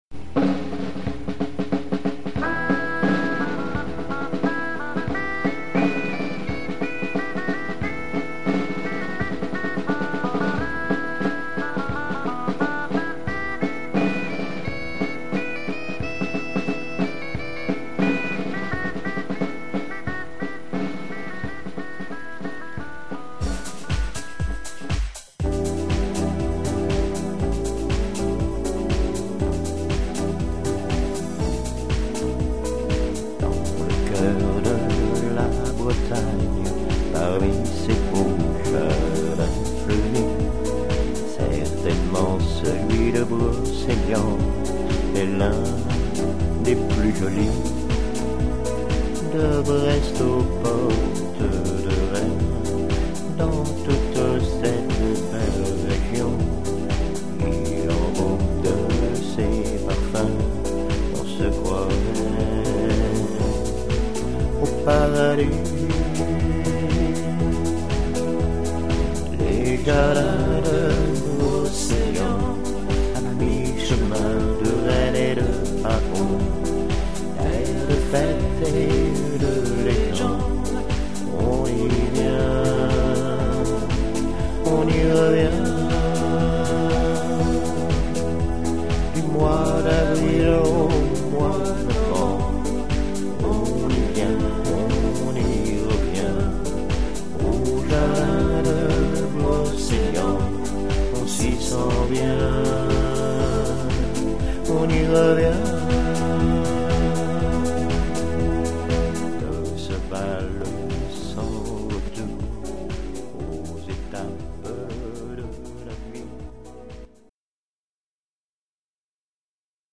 Intro thème bagad son Cornemuse